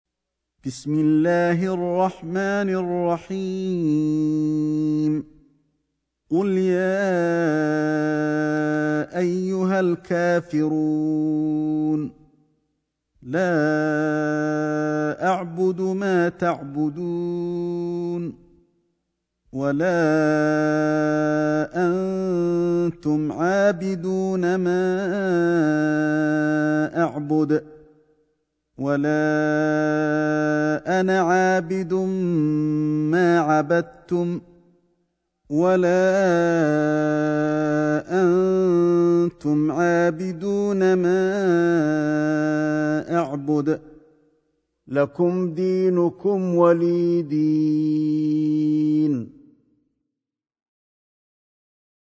سورة الكافرون > مصحف الشيخ علي الحذيفي ( رواية شعبة عن عاصم ) > المصحف - تلاوات الحرمين